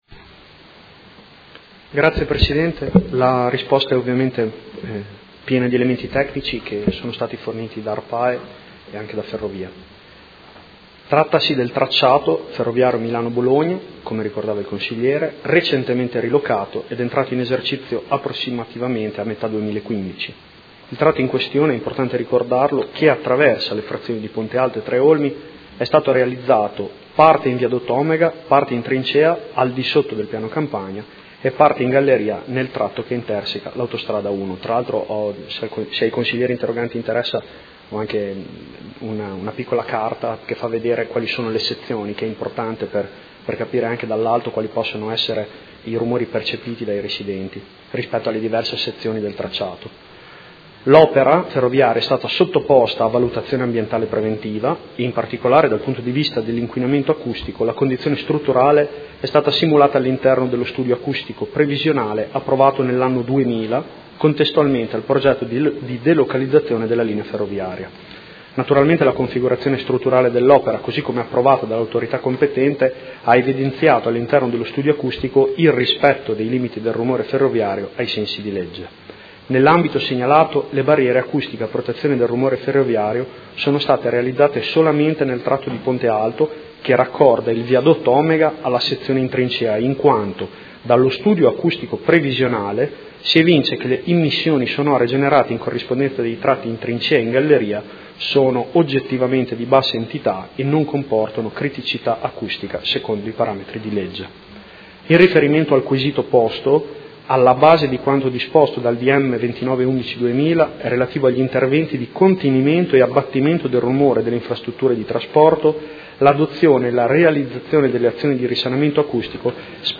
Seduta del 18/05/2017. Risponde a interrogazione del Consigliere Montanini (CambiAMOdena) e dal Consigliere De Lillo (PD) avente per oggetto: Inquinamento acustico da traffico ferroviario della nuova linea che attraversa le Frazioni di: Ponte Alto/Freto/Tre Olmi